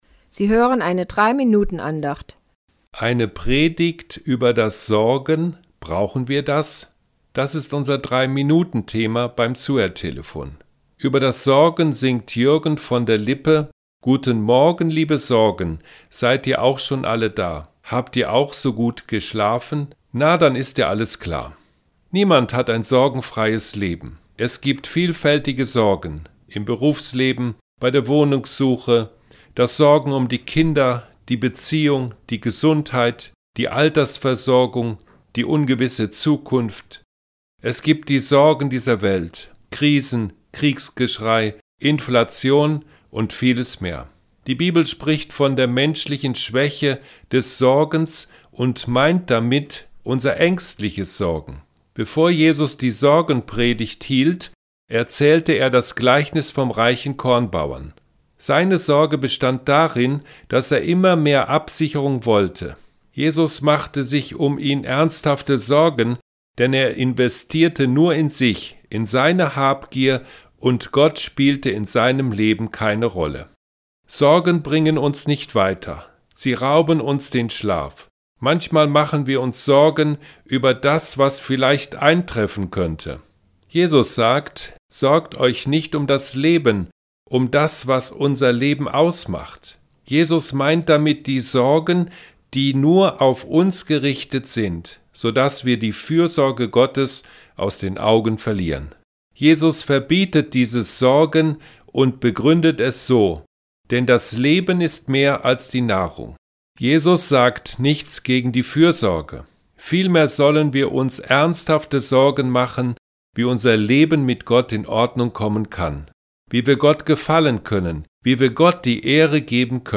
M24-11Eine Predigt über Das Sorgen – Brauchen Wir Das